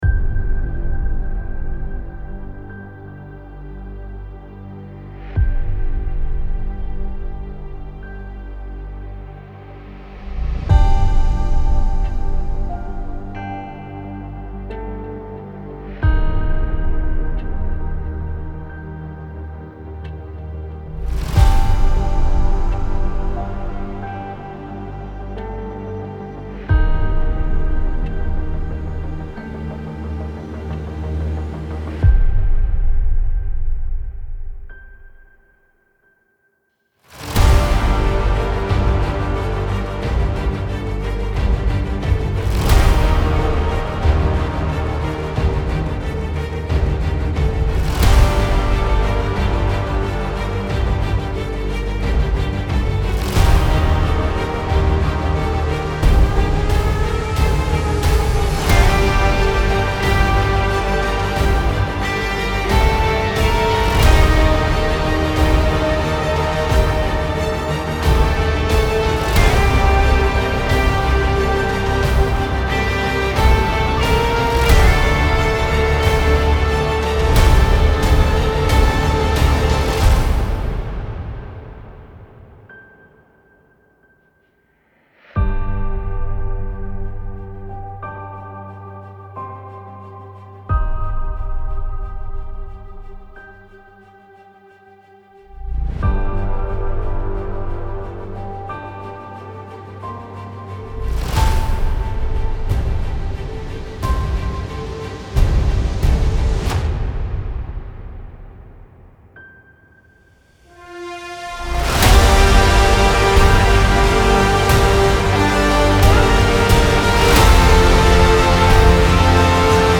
سبک اپیک , موسیقی بی کلام
موسیقی بی کلام ارکسترال